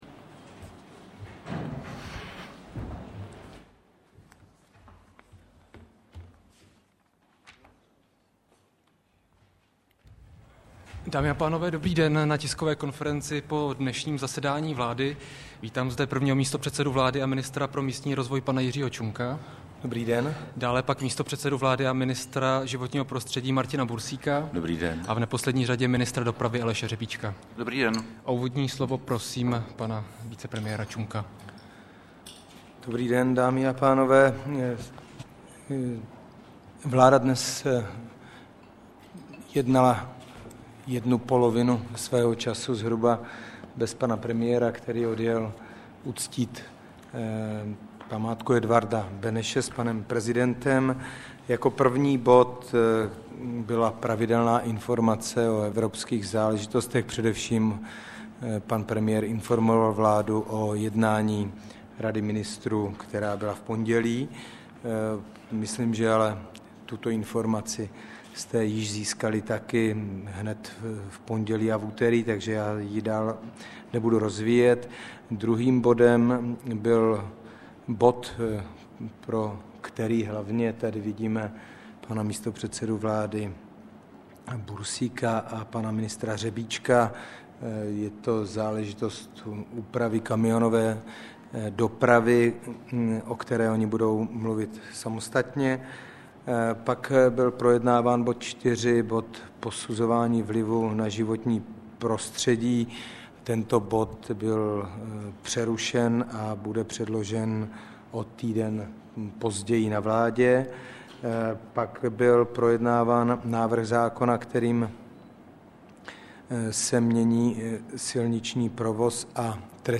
Tisková konference po jednání vlády ČR 3. září 2008